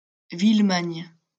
Villemagne (French pronunciation: [vilmaɲ]